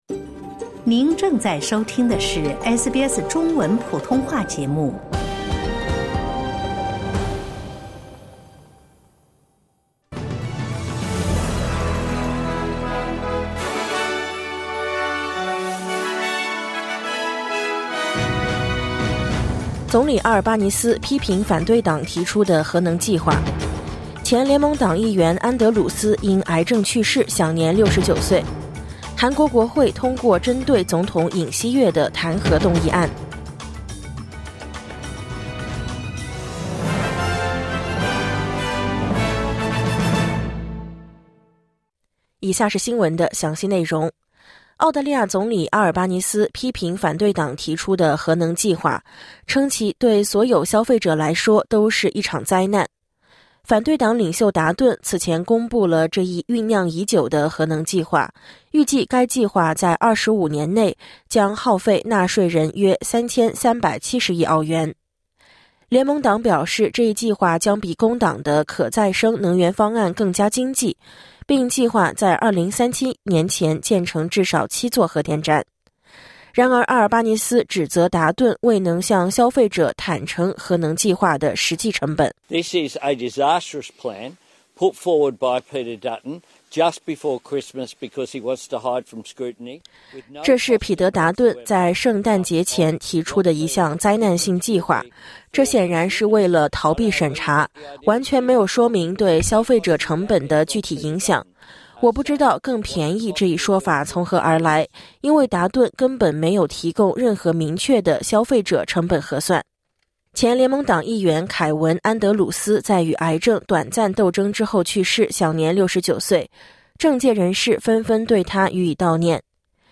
SBS早新闻（2024年12月15日）